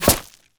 bullet_impact_gravel_01.wav